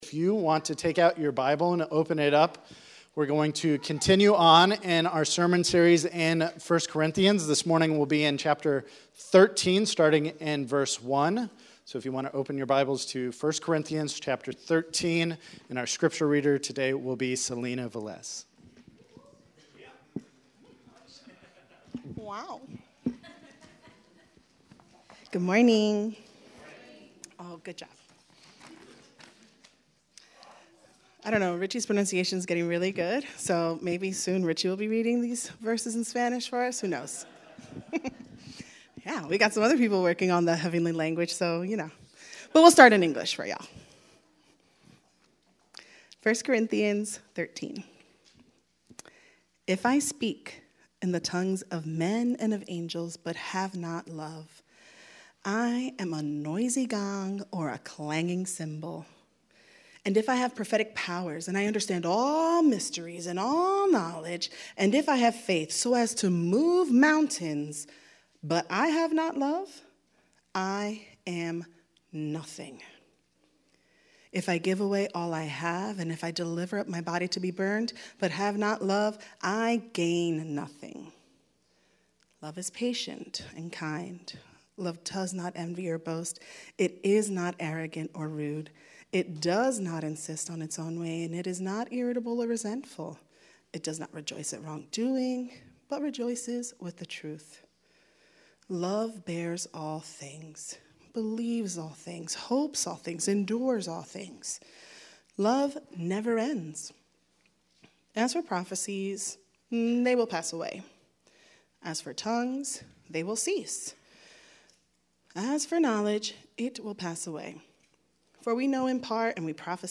Sermons - Grace City Church of the Northeast